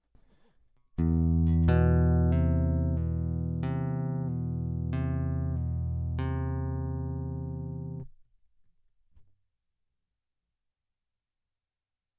now that the subsix is better isolated the second spike is no longer present. the frequency of the interference is significantly reduced.
in fact, on these you can hear the graphtech mistrigger when the subsix does not.